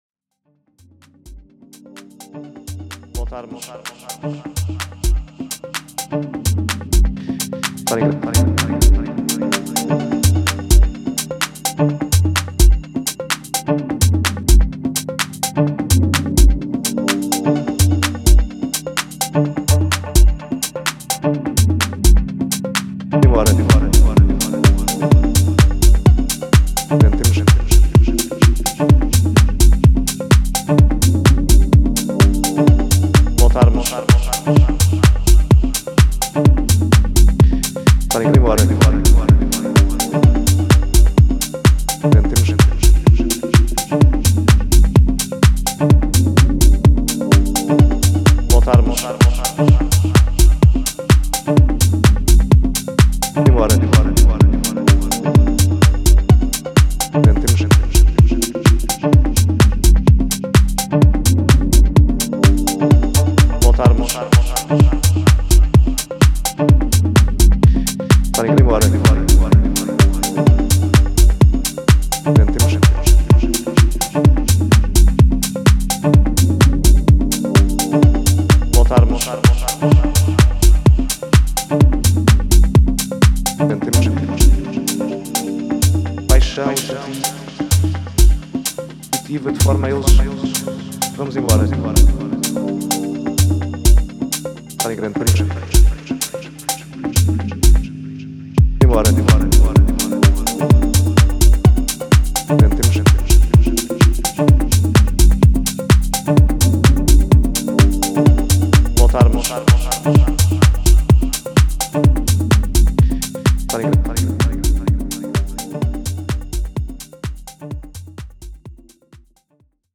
いずれの楽曲からもそれぞれの個性が溢れており、広範なタイプのミニマル・ハウスを収録した一枚です！